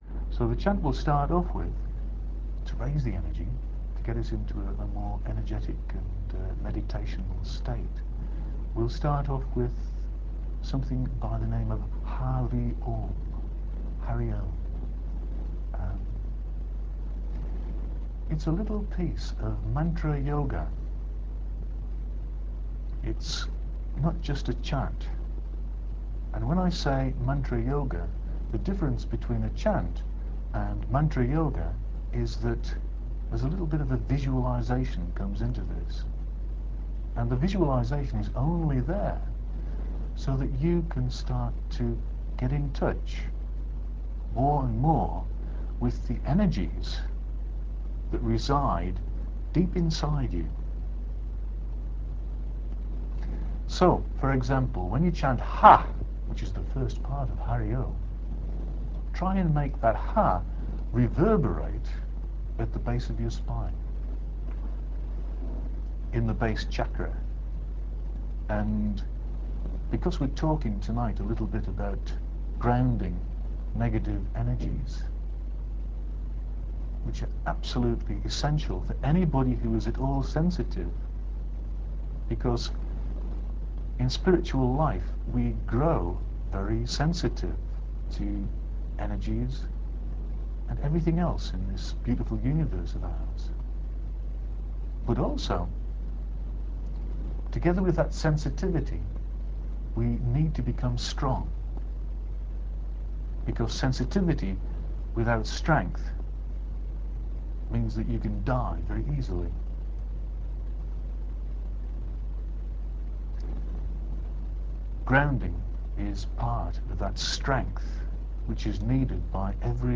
The Energy Enhancement Audio Talk On Grounding By Download!